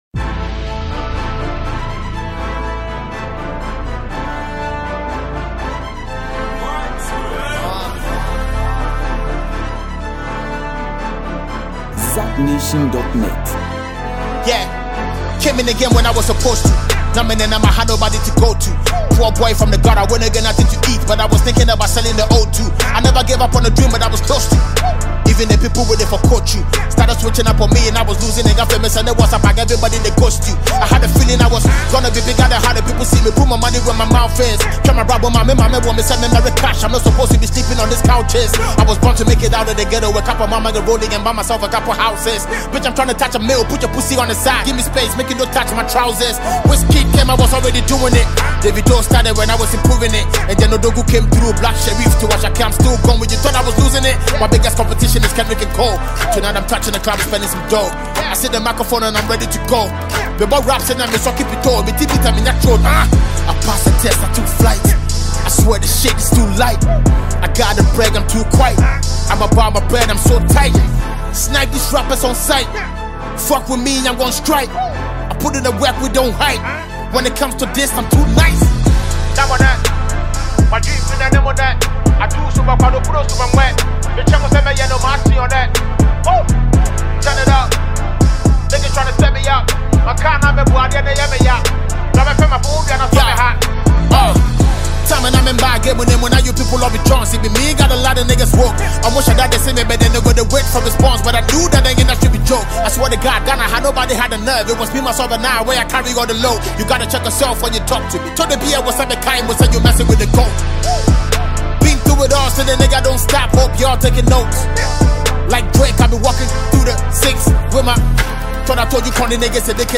Ghanaian rapper
clever wordplay and infectious beats